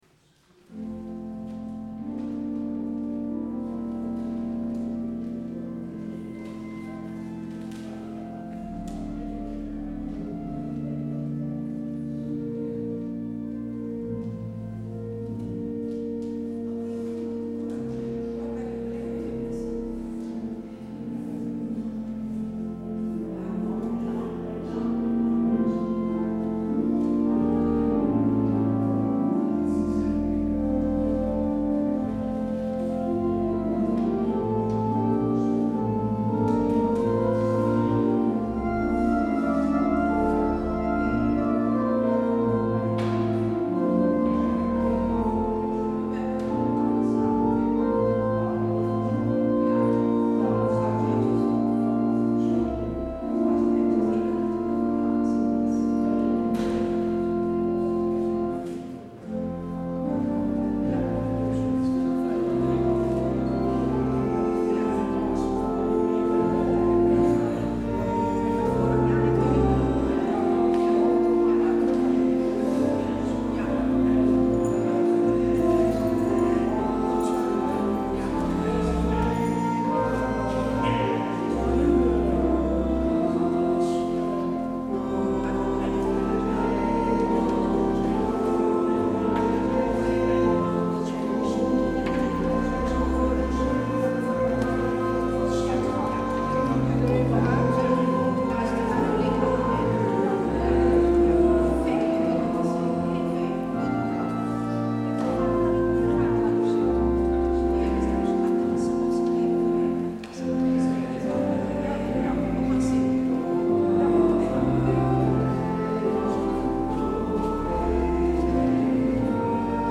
Op het orgel speelt
 Luister deze kerkdienst hier terug
Als openingslied hoort u: LB 134 vers 1, 2 en 3.